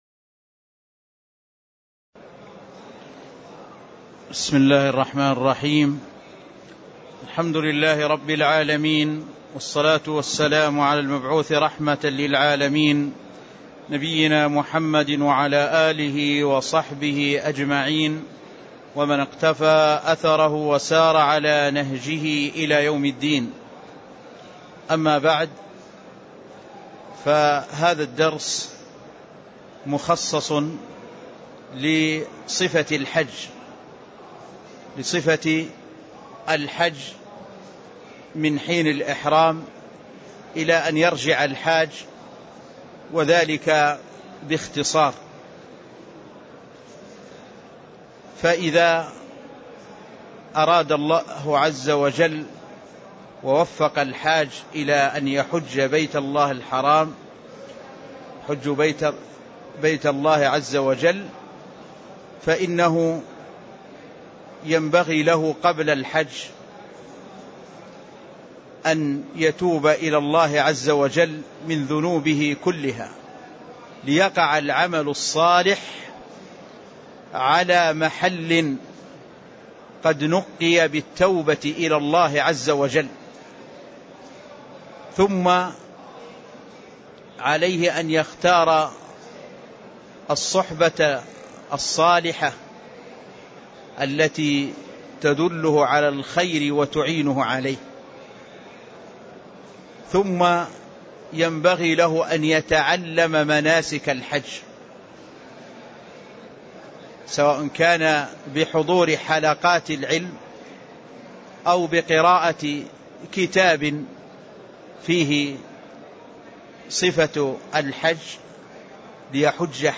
تاريخ النشر ٢٦ ذو القعدة ١٤٣٥ هـ المكان: المسجد النبوي الشيخ